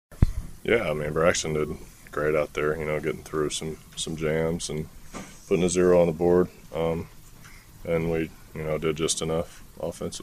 Bryan Reynolds liked the way Braxton Ashcraft battled through a tough three innings of work.